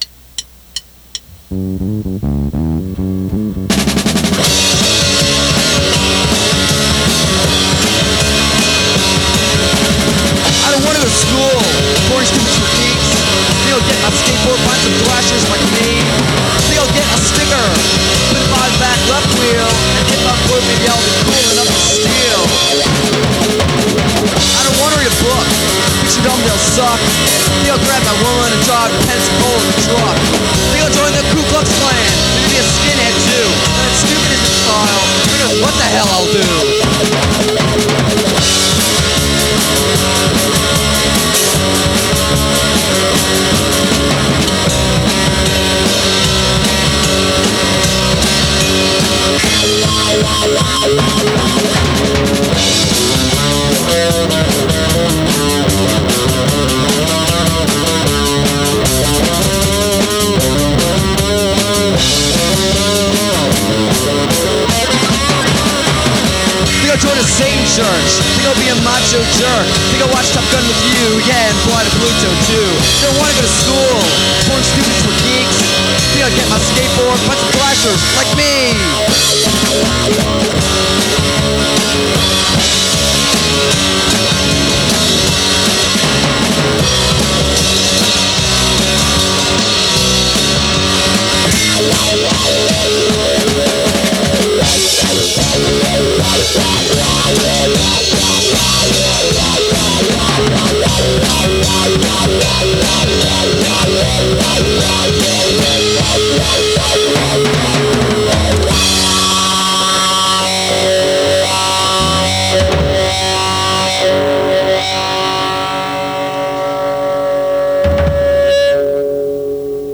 Recorded in a concrete shed in Lake Oaks back in 1989
guitar
drums